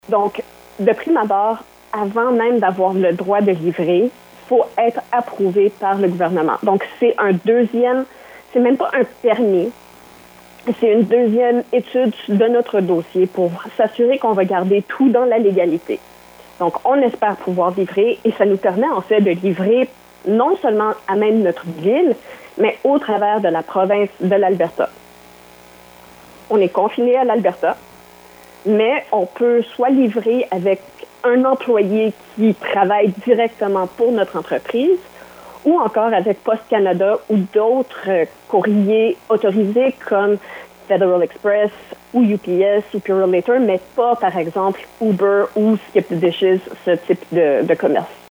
Vous pouvez écouter un court extrait